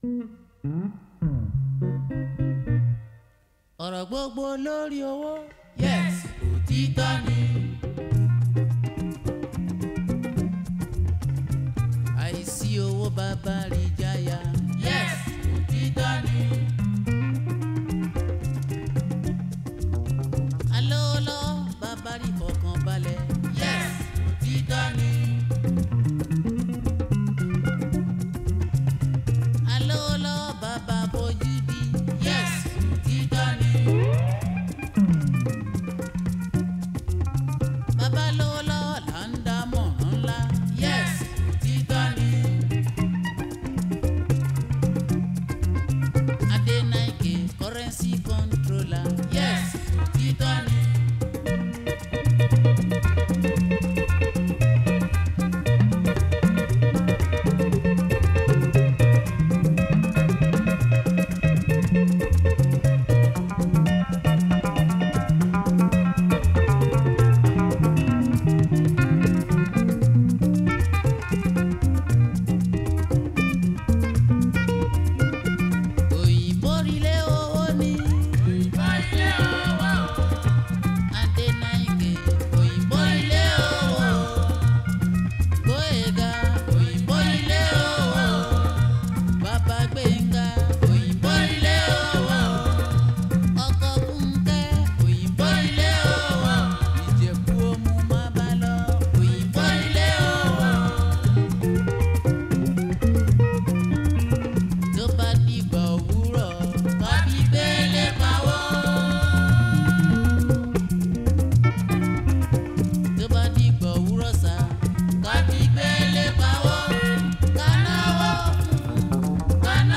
is a Nigerian jùjú singer